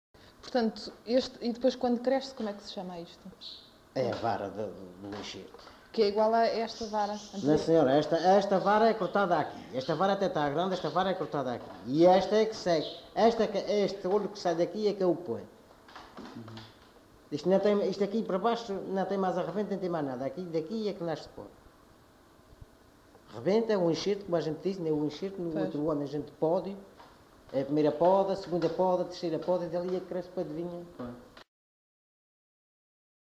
LocalidadeBiscoitos (Angra do Heroísmo, Angra do Heroísmo)